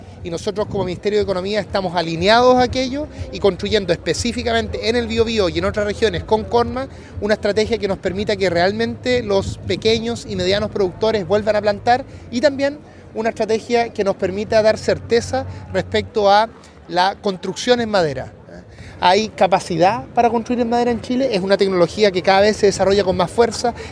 Hasta la zona llegaron los ministros de Economía, Nicolás Grau; y de Agricultura, Esteban Valenzuela, quienes emitieron discursos ante los presentes.